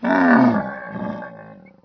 c_camel_atk3.wav